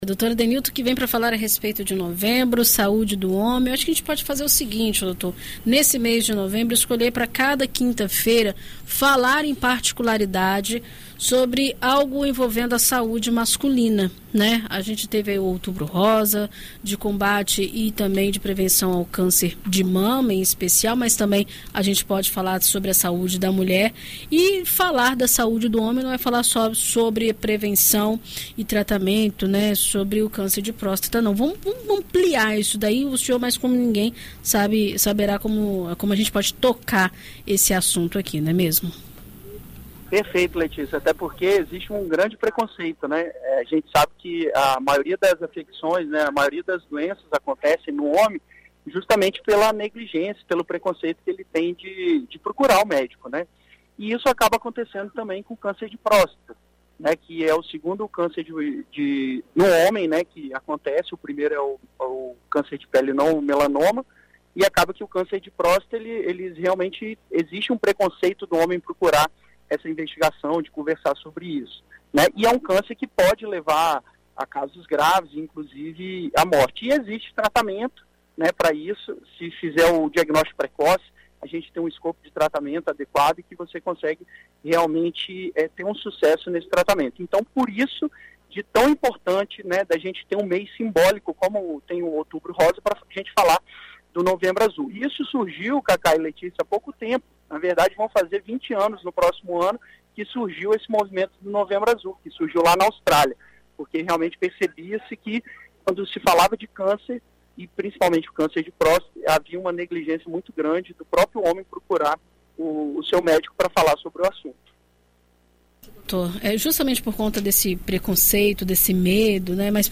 O médico intensivista